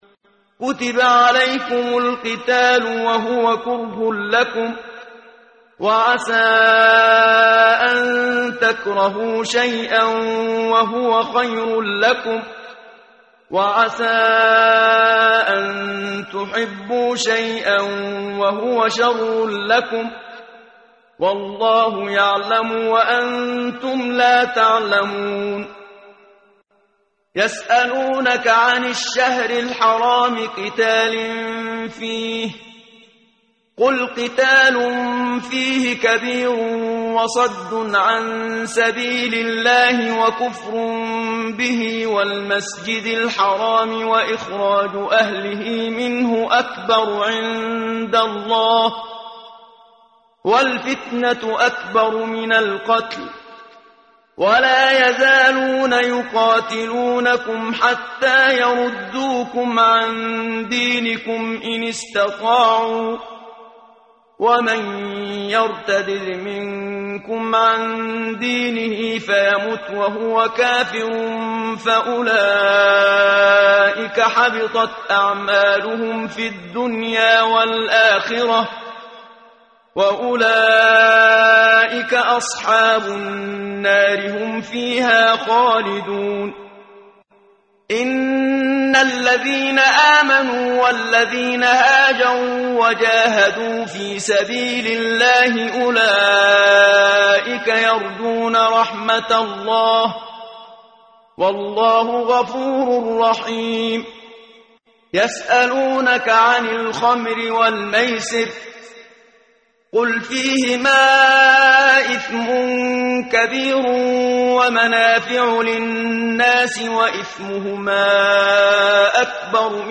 ترتیل صفحه 34 سوره مبارکه بقره (جزء دوم) از سری مجموعه صفحه ای از نور با صدای استاد محمد صدیق منشاوی
quran-menshavi-p034.mp3